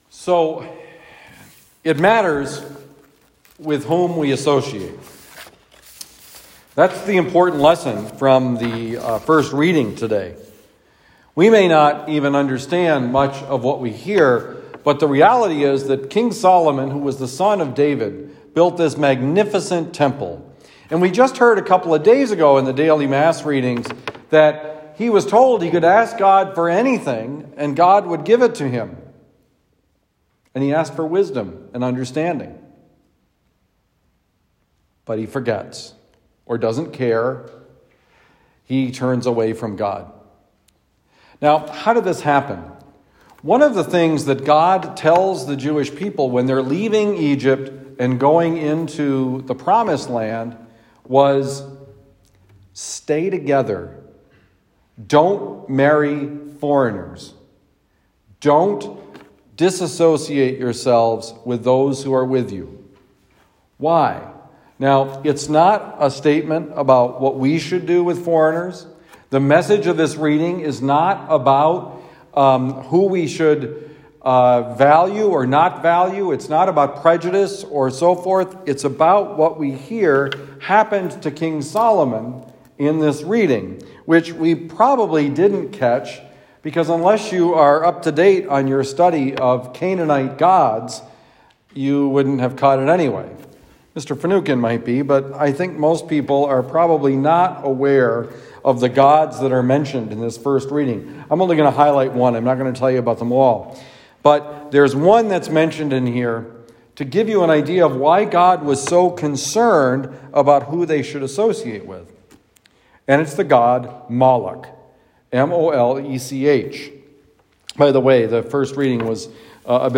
Homily given at Lasalle Retreat Center, Glencoe, Missouri.